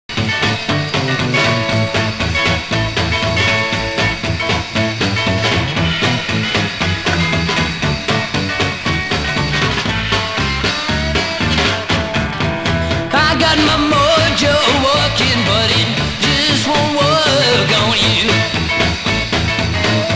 (税込￥6380)   STEREO